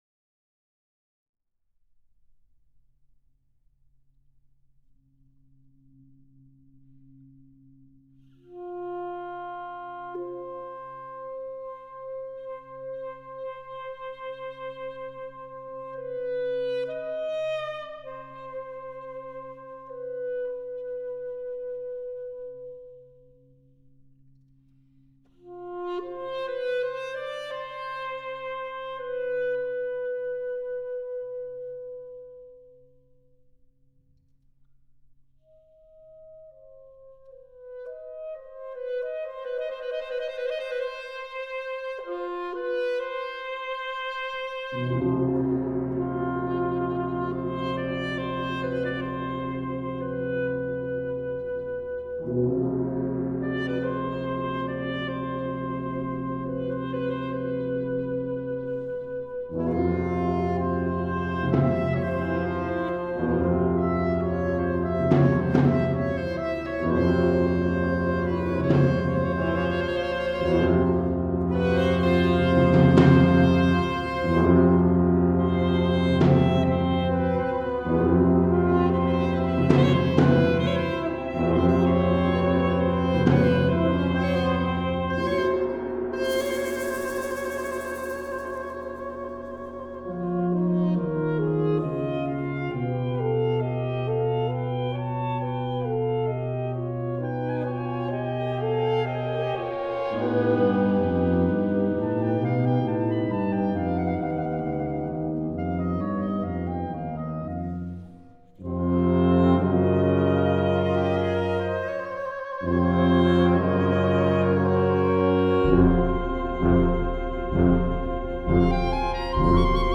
Partitions pour septuor flexible.